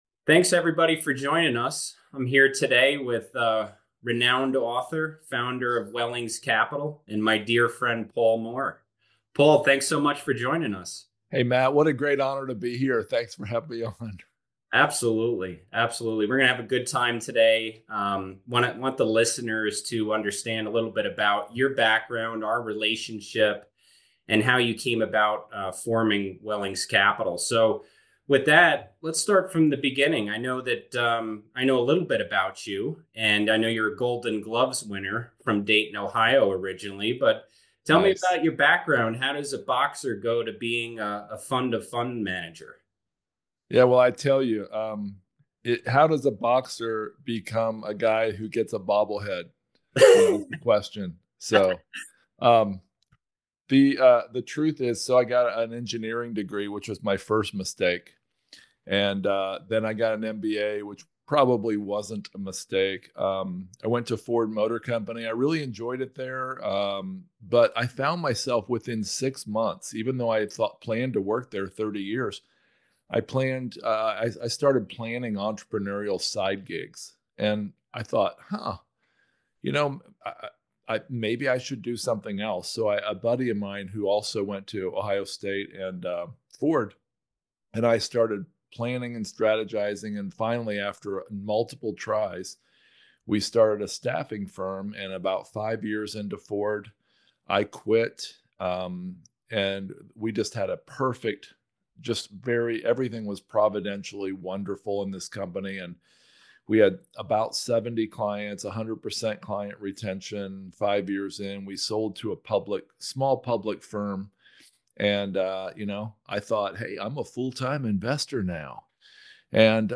The podcast aims to provide exclusive interviews and behind-the-scenes strategies from industry leaders, offering insights into effective value-add approaches.